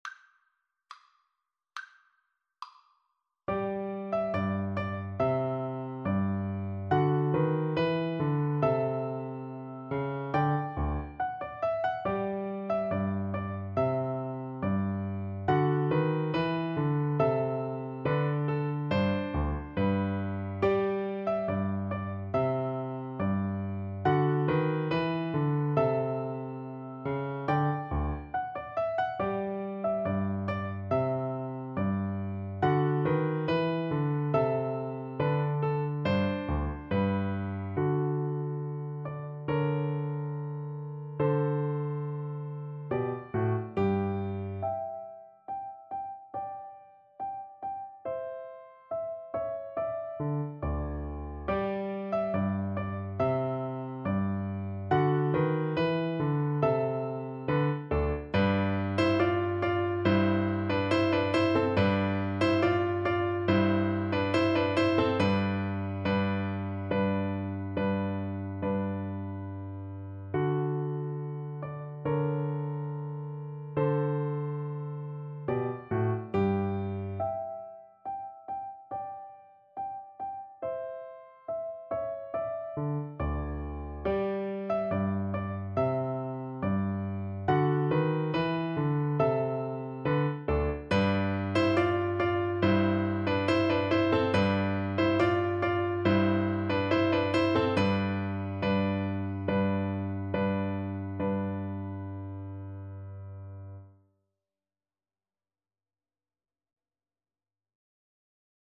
G major (Sounding Pitch) (View more G major Music for Cello )
2/4 (View more 2/4 Music)
Classical (View more Classical Cello Music)